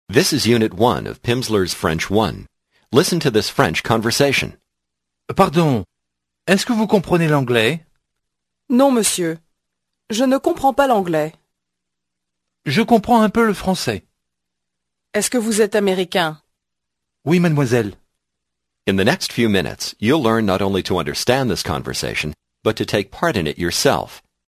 Аудио курс для самостоятельного изучения французского языка.